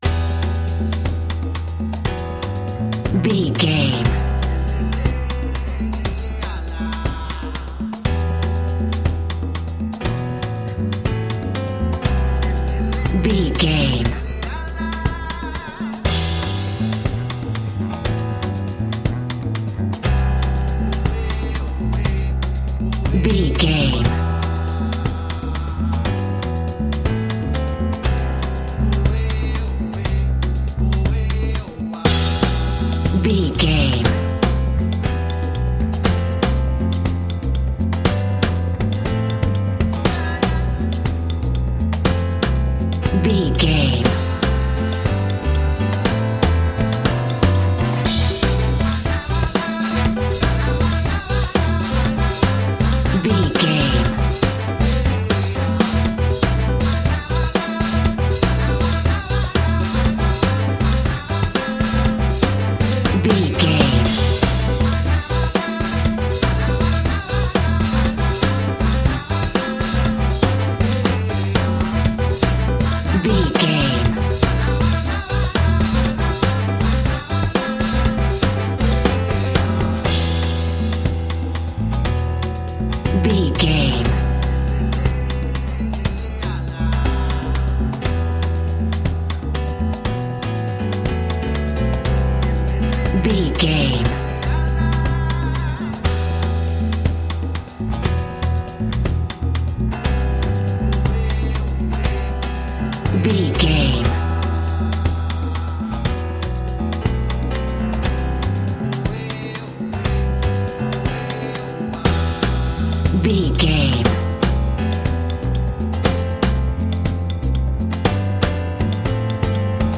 Aeolian/Minor
maracas
percussion spanish guitar
latin guitar